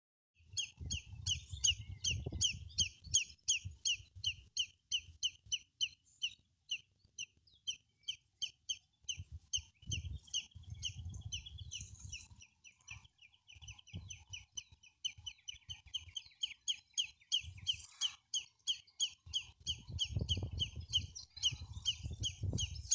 Поручейник (Tringa stagnatilis). Вокализация.Поручейник (Tringa stagnatilis). Вокализация.
Самец токует, летая высоко и широко, с мелодичными повторяющимися криками.